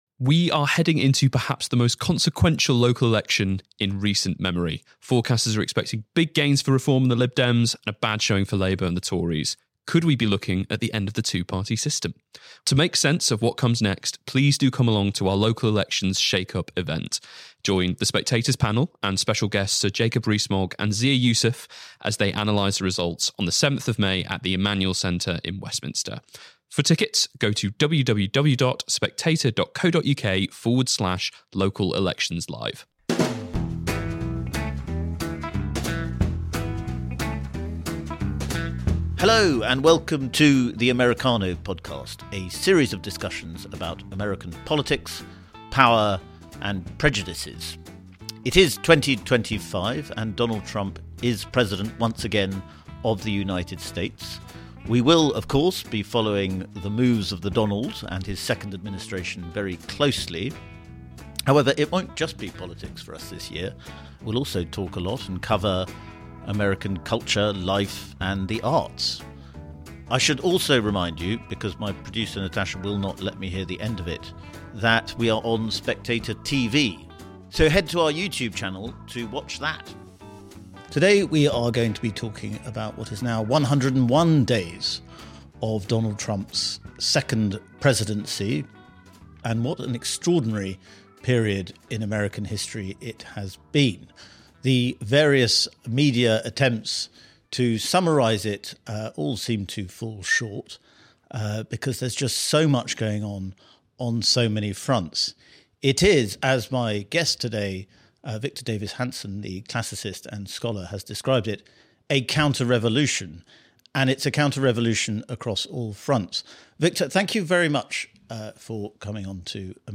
Victor Davis Hanson joins Spectator TV to talk about the first 101 days of Donald Trump’s second presidency, describing it as a bold counterrevolution against decades of cultural, political, and economic drift.